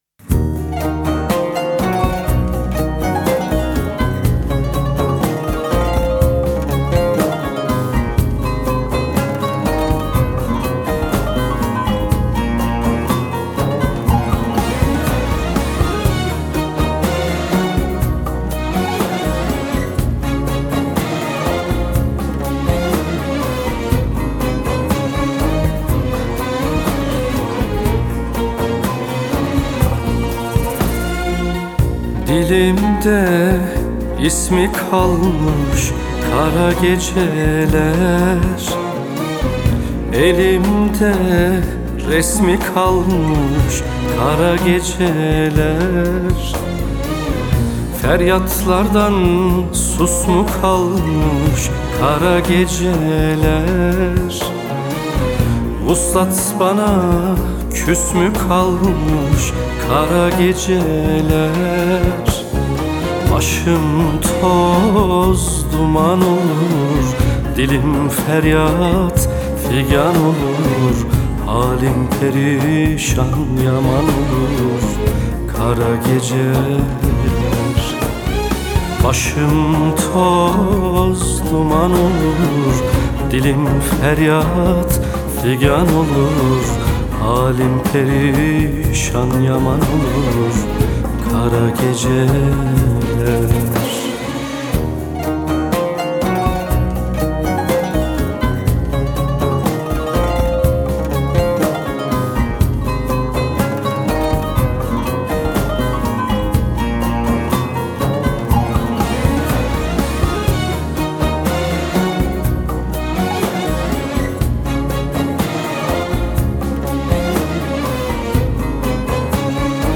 آهنگ ترکیه ای